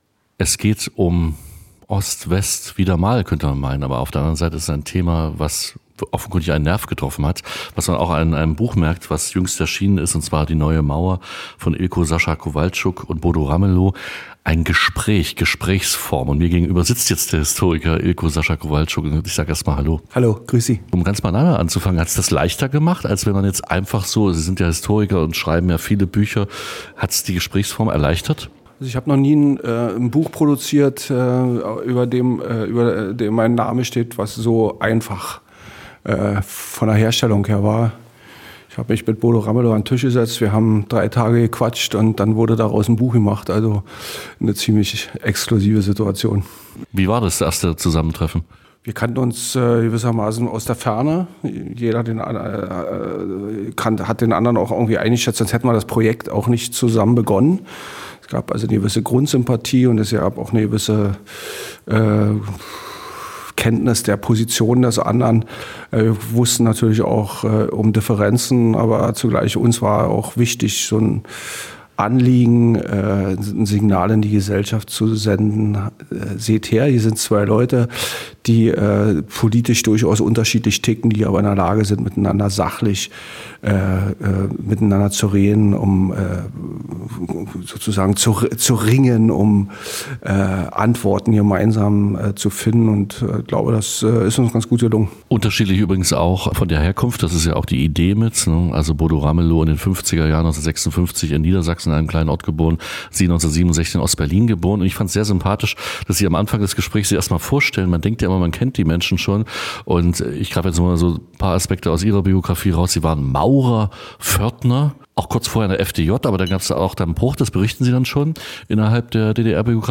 INTERVIEW Ilko Sascha Kowalczuk.mp3